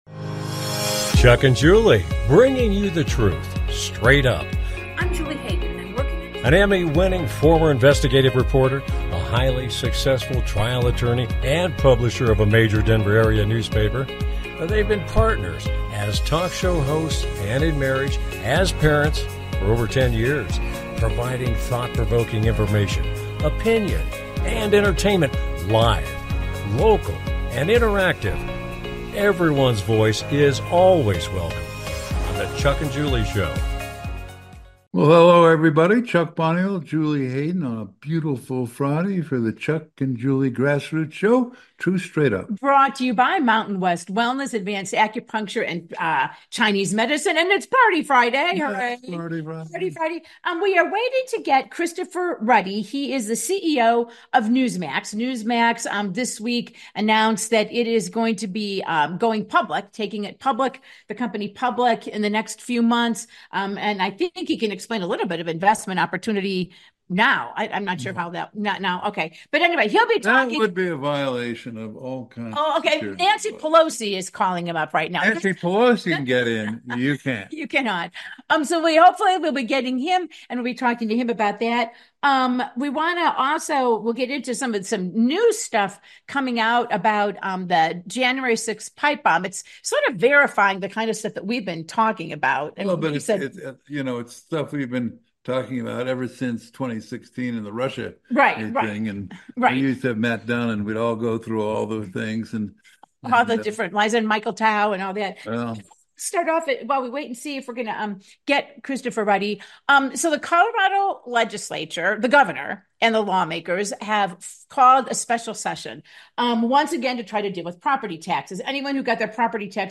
With Guest, Christopher Ruddy the CEO of Newsmax Media